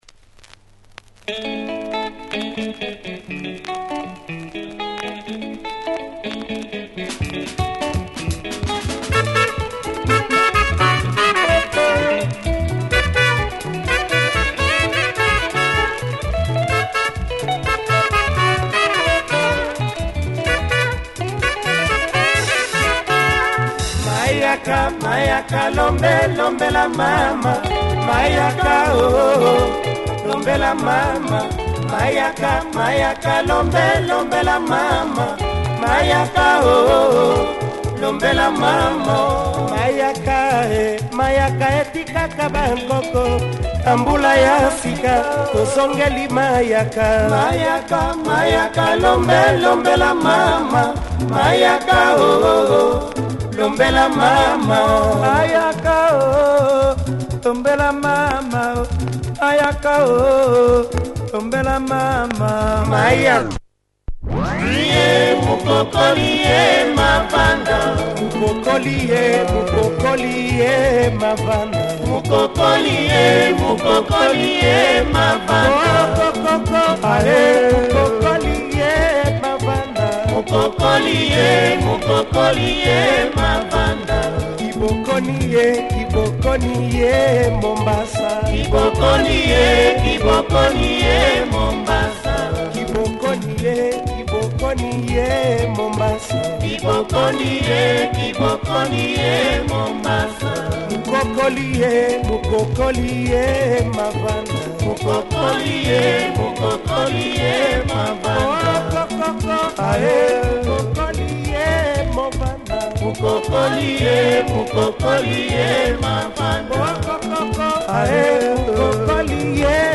Sassy